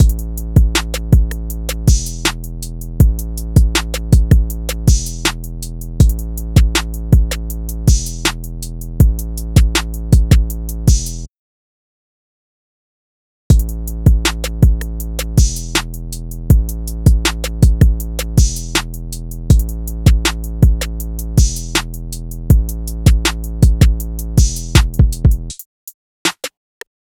TRAP Mixing And Mastering DRUMS | FLP and Multi Track
Sin Procesamiento
Sin-Mixing-ni-Mastering.wav